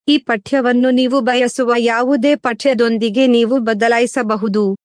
Professionelle Sprachausgabe zum Vorlesen und Vertonen beliebiger Texte
Professionelle, natürlich klingende männliche und weibliche Stimmen in vielen Sprachen, die kaum mehr von einem menschlichen Sprecher zu unterscheiden sind.